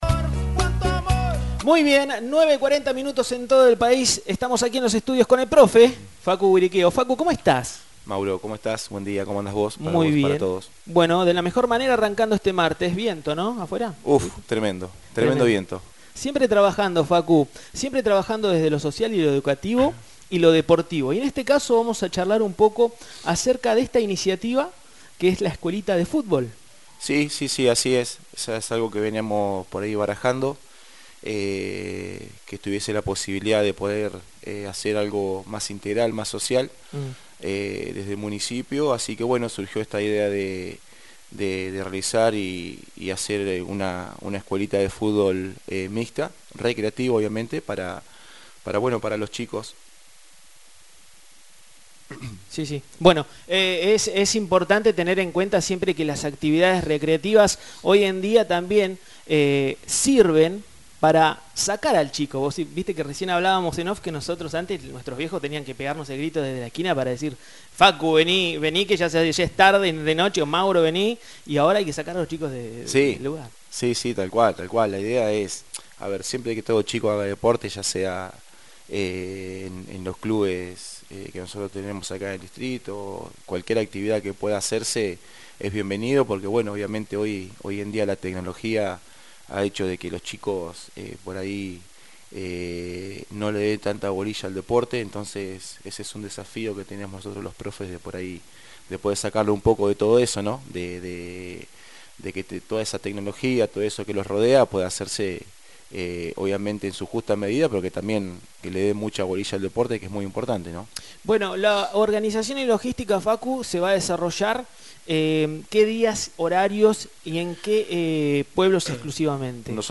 Escuchá la nota completa a continuación.